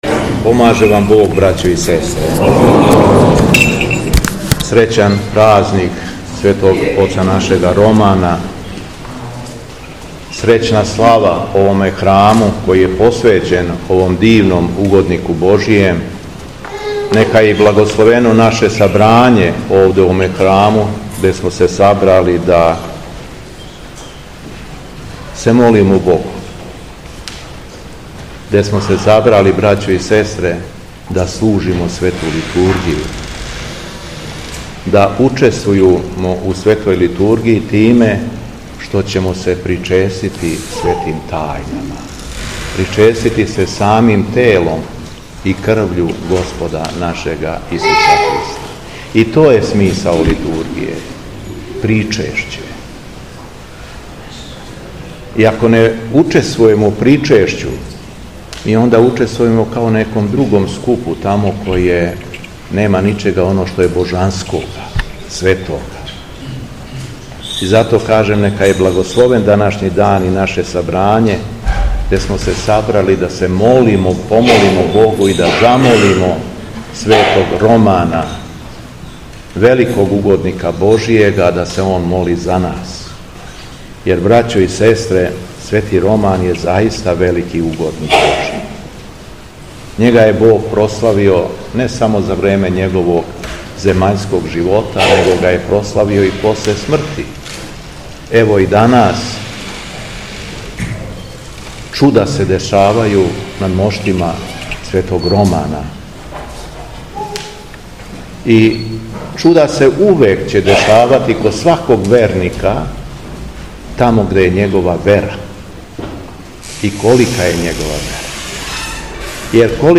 Беседа Његовог Високопреосвештенства Митрополита шумадијског г. Јована
Након прочитаног Јеванђелског зачала високопреосвећени митрополит се обратио верном народу следећим речима: